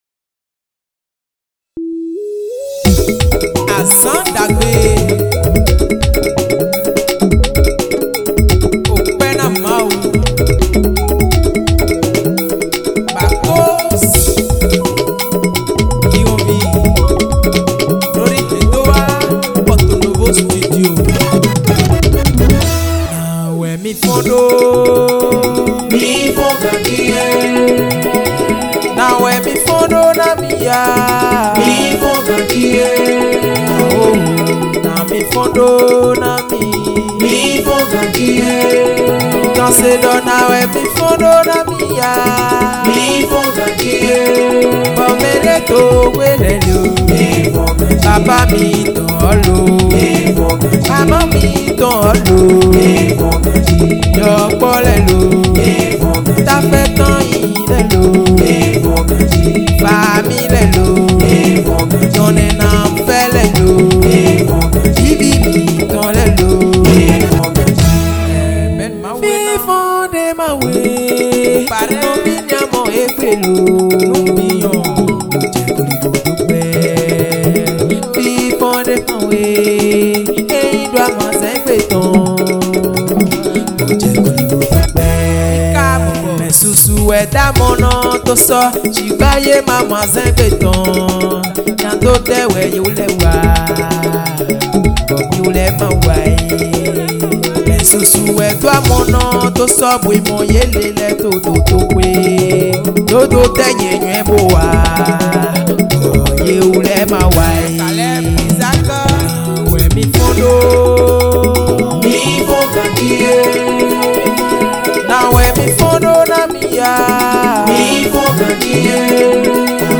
Gospel Mp3